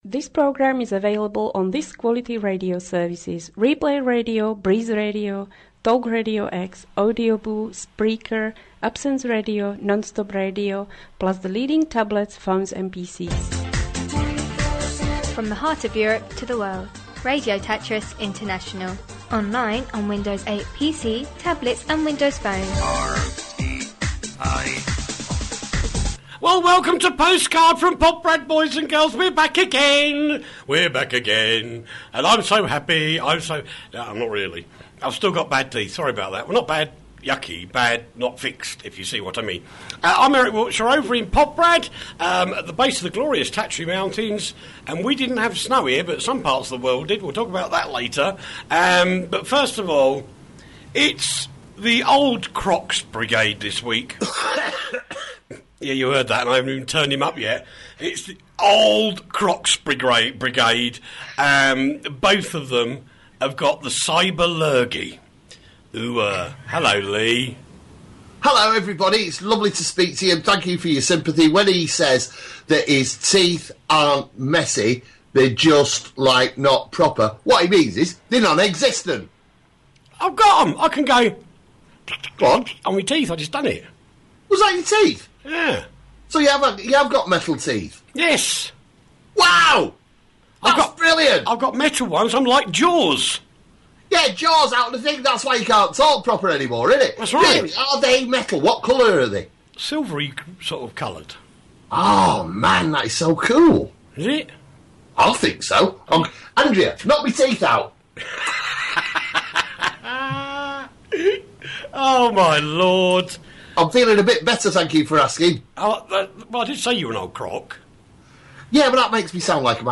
Everything from Tech to Tabloid News.